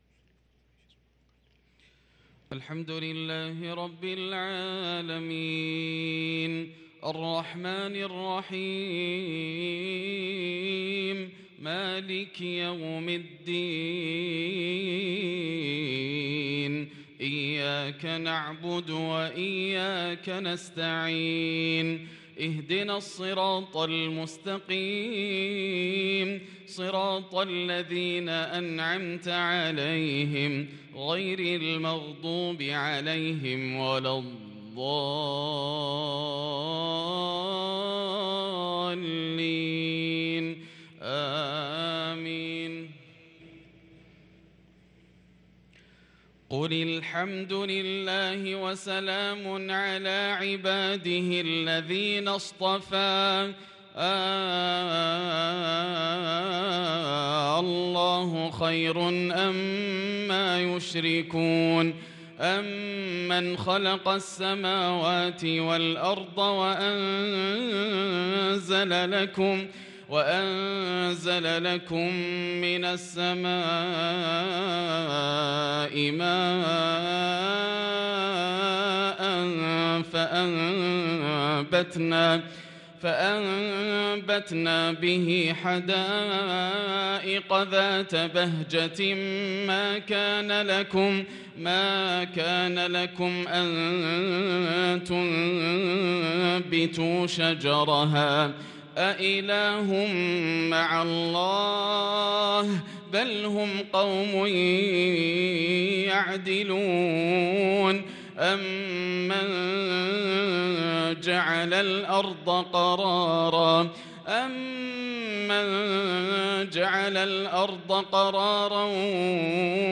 صلاة العشاء للقارئ ياسر الدوسري 21 شوال 1443 هـ